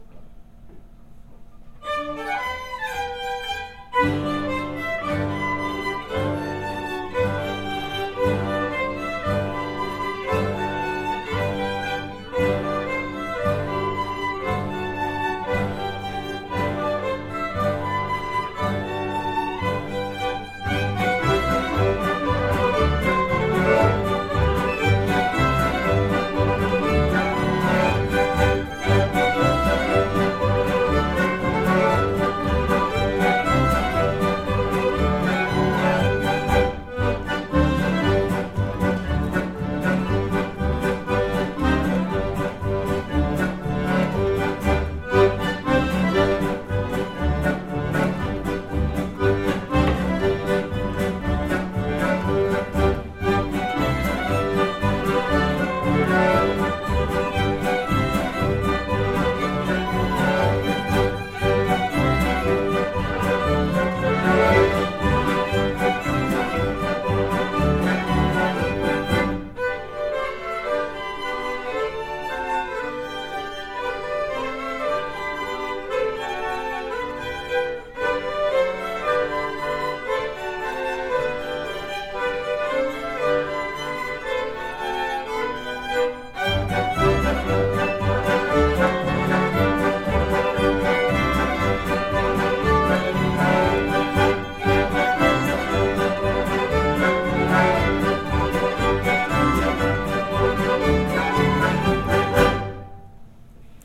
Nauhoitimme kilpailukappaleemme viimeisessä harjoituksessa ennen kilpailua.